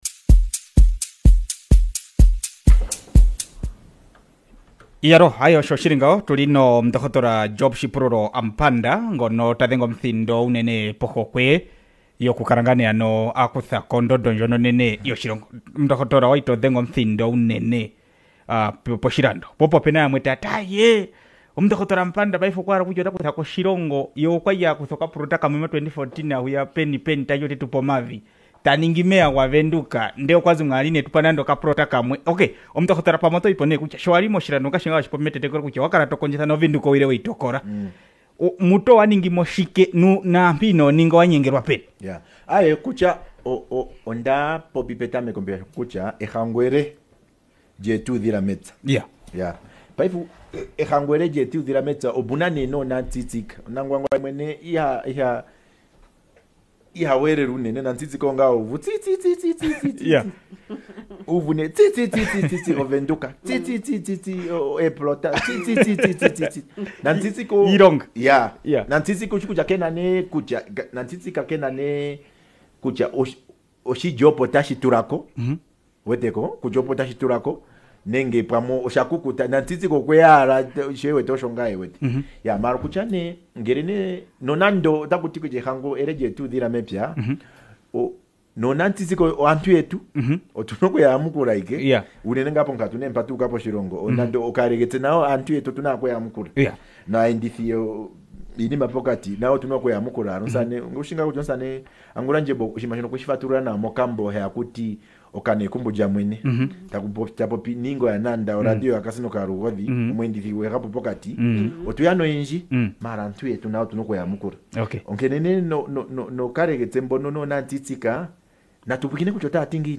Affirmative Repositioning Movement leader Dr Job Amupanda Dr. Job Shipululo Amupanda plans to run for the presidency in the next year's presidential elections and take over the highest office. Here is the link, if you have missed the interview.